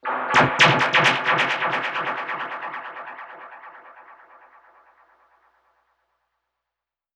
Index of /musicradar/dub-percussion-samples/134bpm
DPFX_PercHit_B_134-05.wav